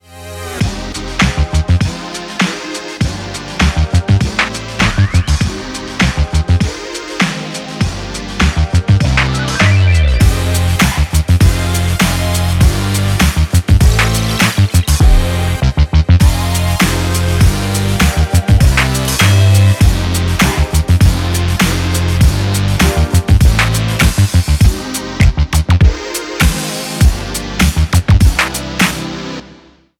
ELECTRO FUNK
Energetic / Upbeat / Dynamic / Funky